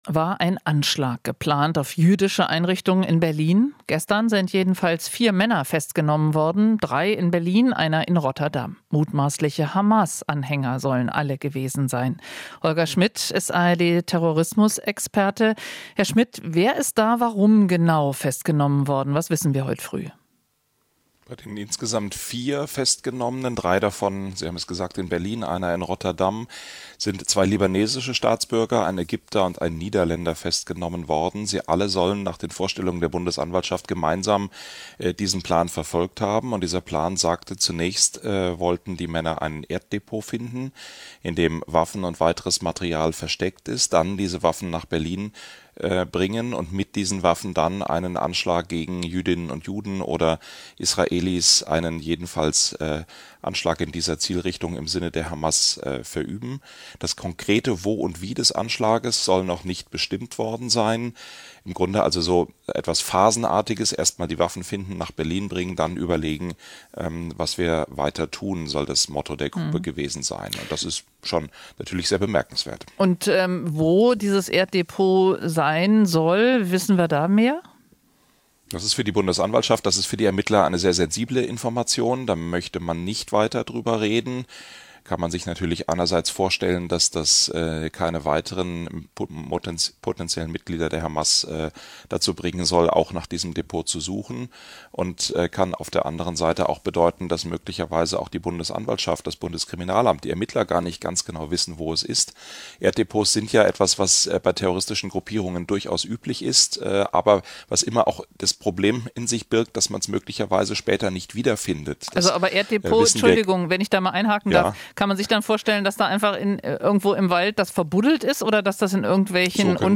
Interview - Mutmaßliche Hamas-Mitglieder in Berlin festgenommen